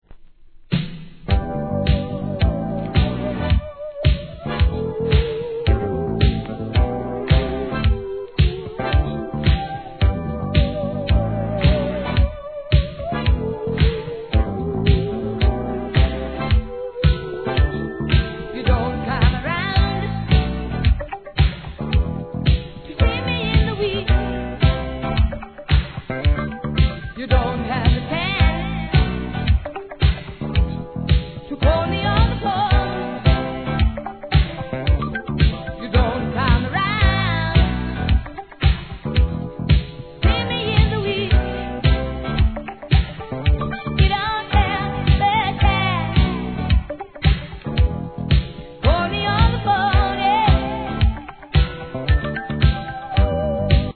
¥ 1,100 税込 関連カテゴリ SOUL/FUNK/etc...
POPなダンス・ナンバー揃いでお勧めです♪